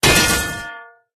metal_damage_03.ogg